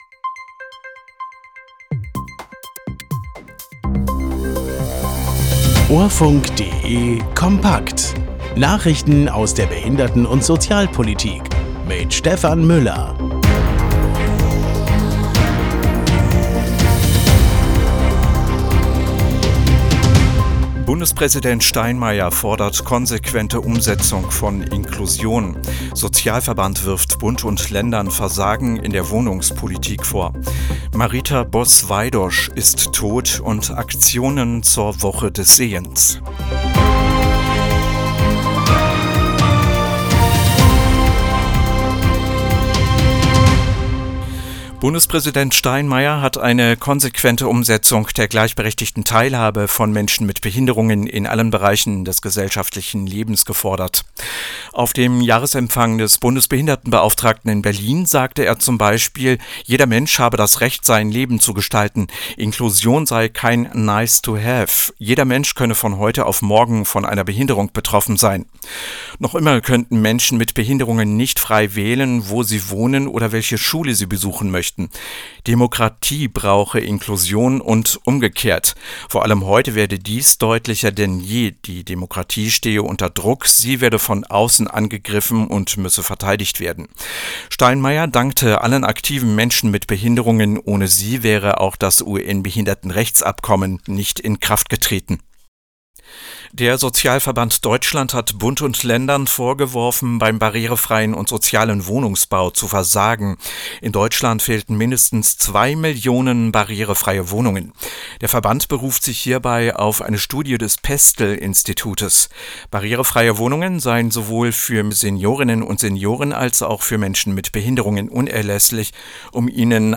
Nachrichten aus der Behinderten- und Sozialpolitik vom 08.10.2025